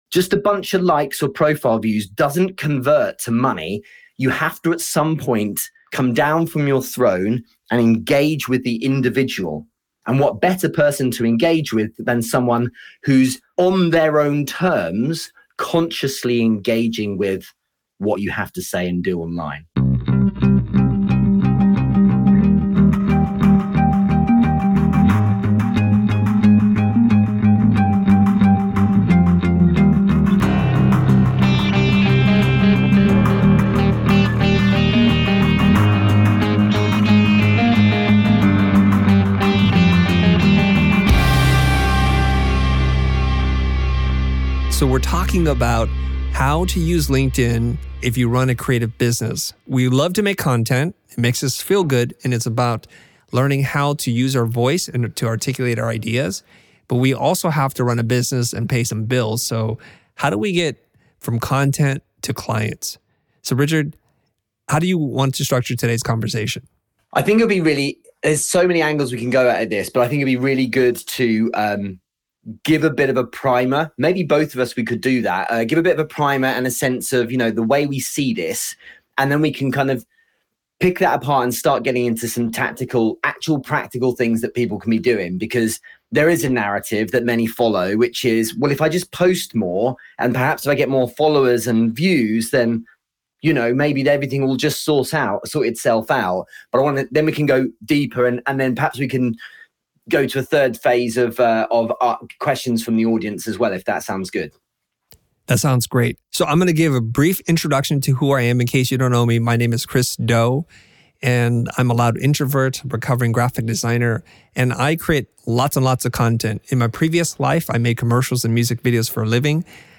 This is the first part of a two part conversation, so make sure you join us for the rest of the conversation in the next episode.